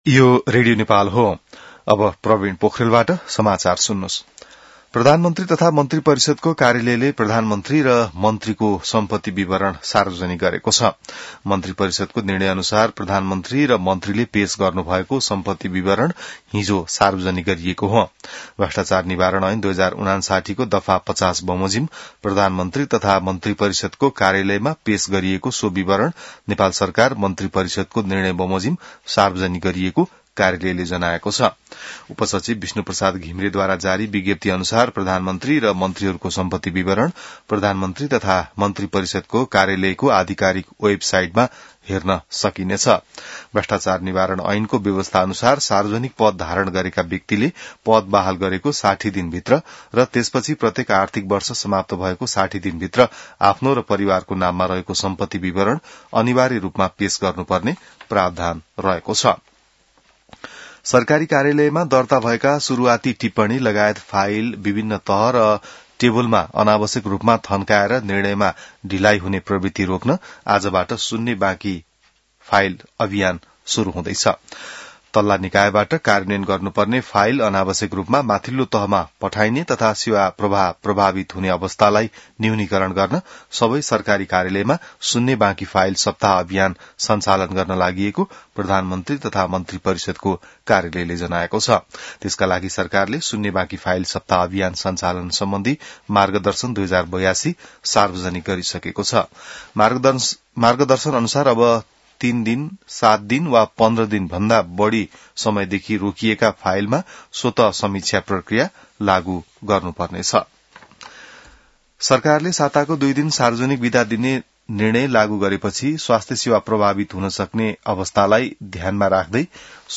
An online outlet of Nepal's national radio broadcaster
बिहान ६ बजेको नेपाली समाचार : ३० चैत , २०८२